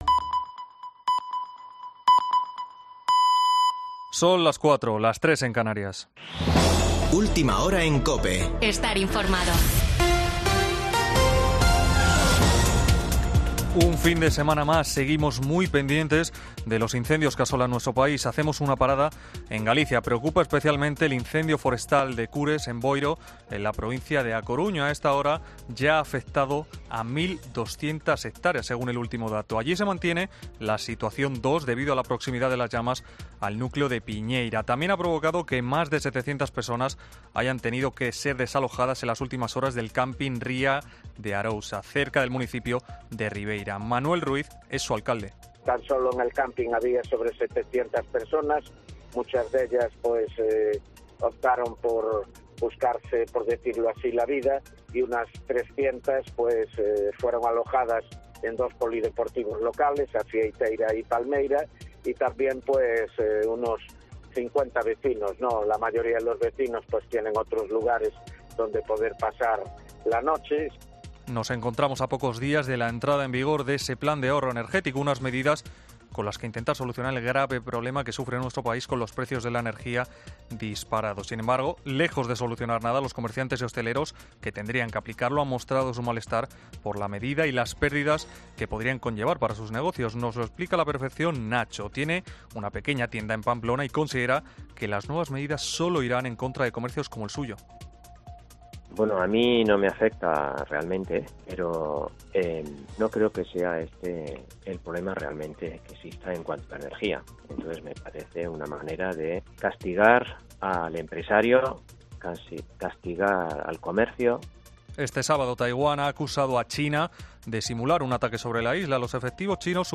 Boletín de noticias de COPE del 6 de agosto de 2022 a las 16.00 horas